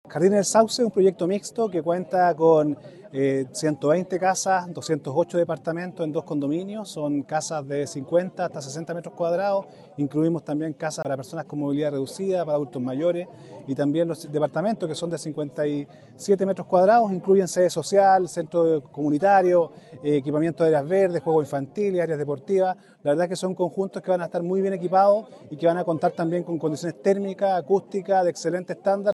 Angelo Montaño Espejo – Director SERVIU Coquimbo
Angelo-Montano-Director-Regional-SERVIU.mp3